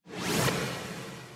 ball_drop.mp3